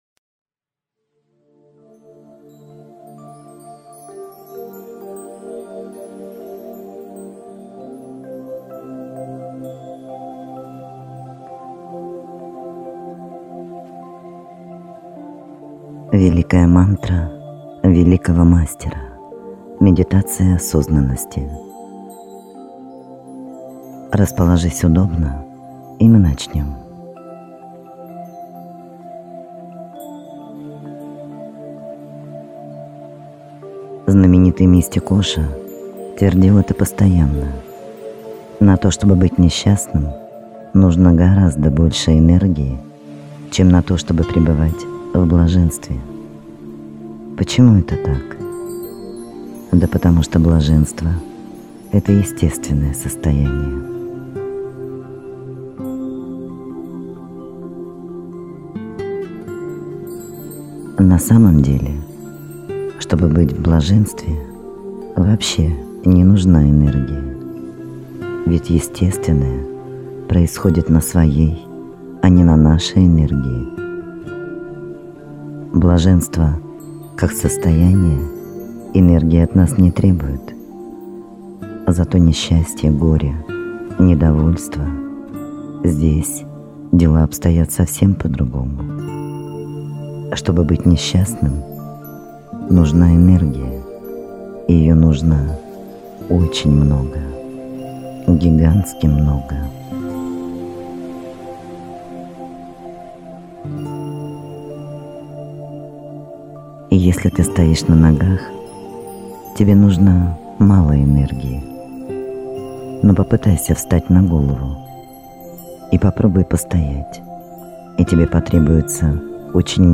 Это Великая мантра Великого Мастера.
Сеанс принимайте в наушниках.